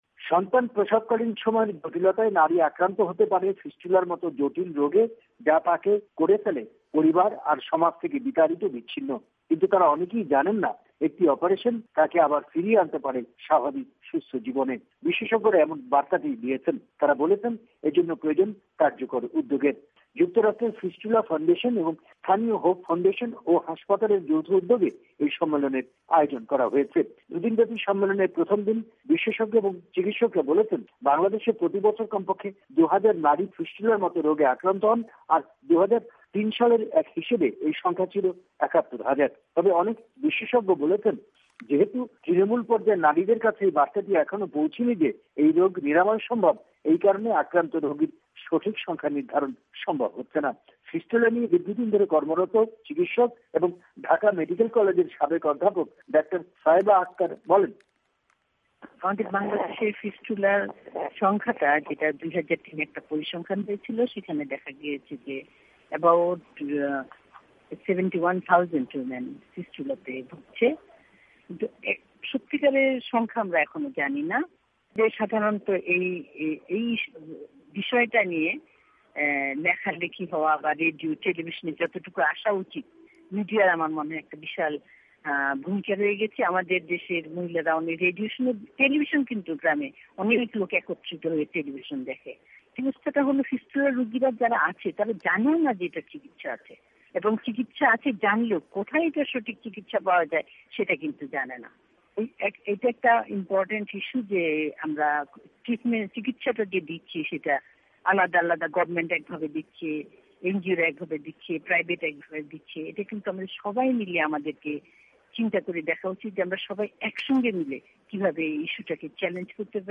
নারীর ফিস্টুলা রোগ নিরাময় করা সম্ভব এমন বার্তা তুলে ধরে পৌছে দেয়ার লক্ষ্যে কক্স্রবাজারে শুরু হয়েছে দুদিন ব্যাপী মাতৃ স্বাস্থ্য ও ফিস্টুলা রোগের চিকিৎসা বিষয়ে আন্তর্জাতিক সম্মেলন। কক্সবাজার থেকে জানাচ্ছেন